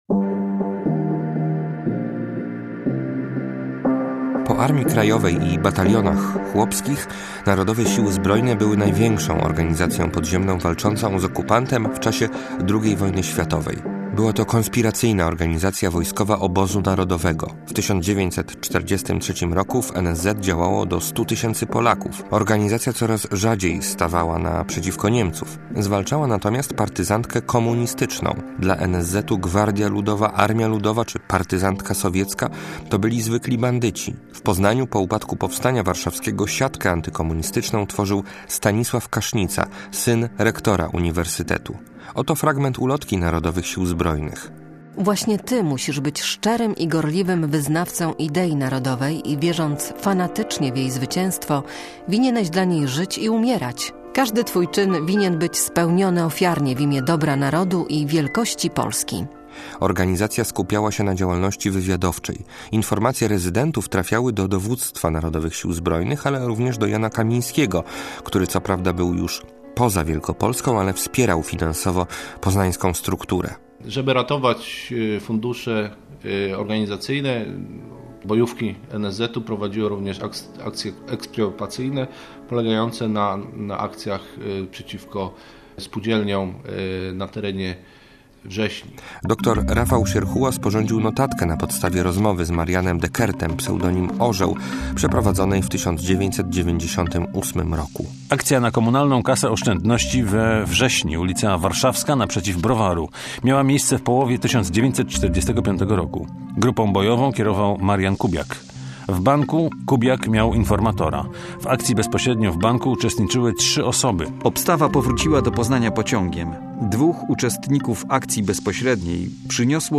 Kasznica - reportaż